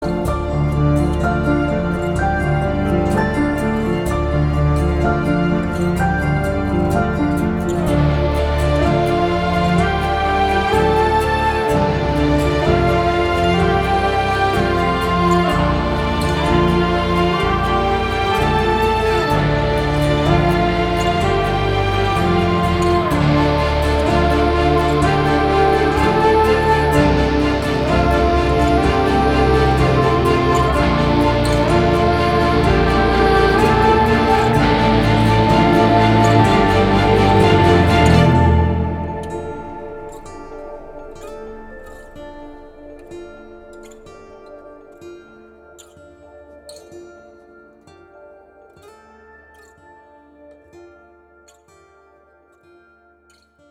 • Качество: 320, Stereo
гитара
грустные
саундтреки
без слов
инструментальные
epic